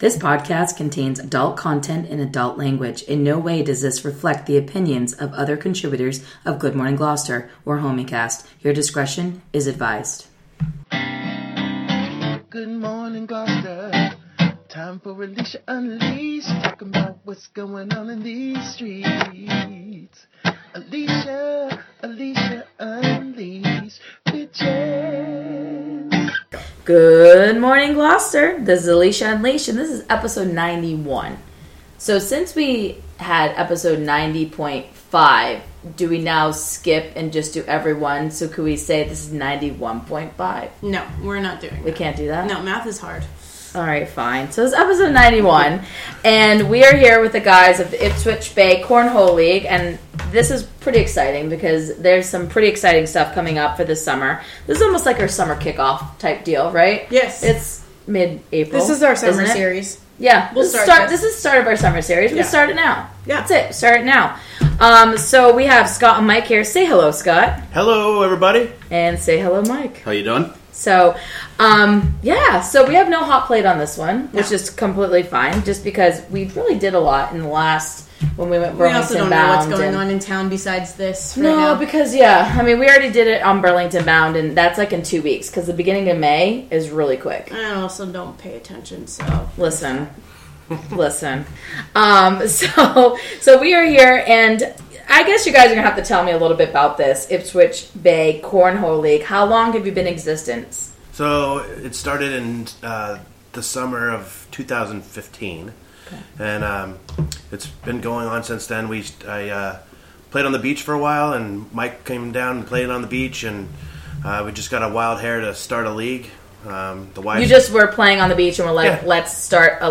We sit down on location